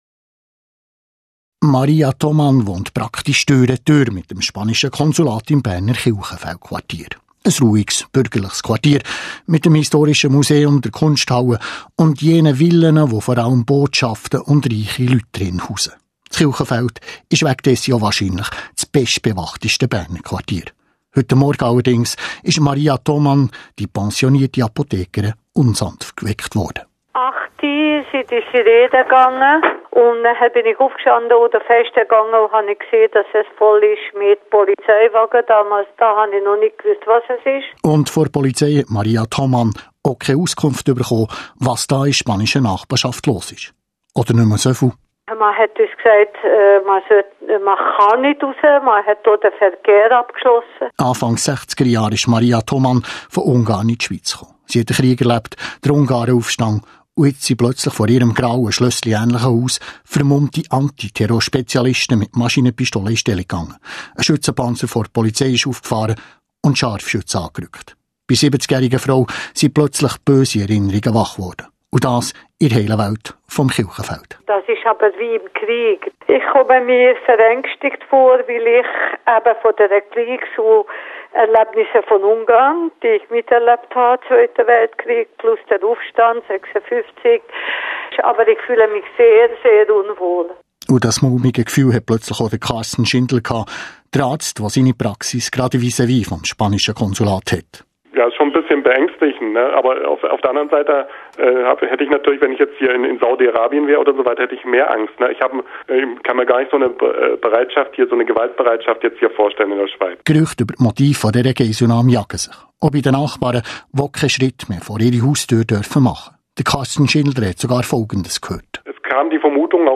mit Stimmen vom Tattag und Tatort.